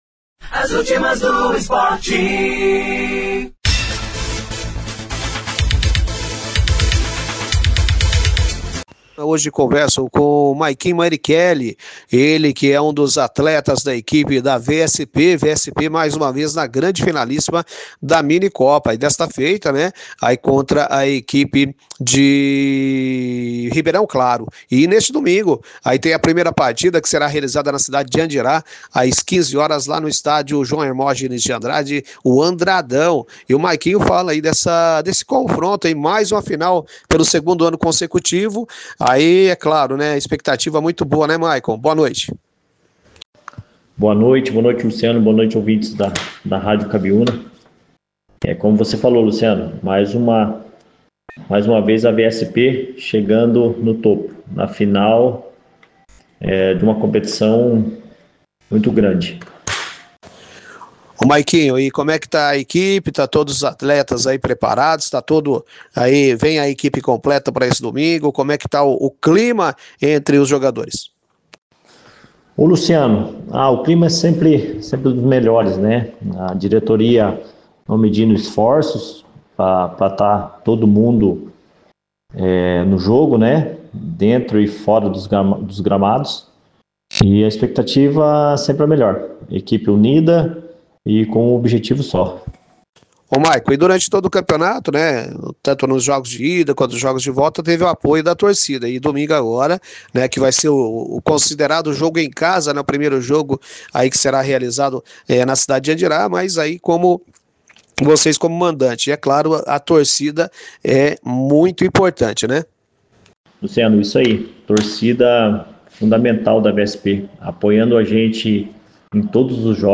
em entrevista à Rádio Cabiúna https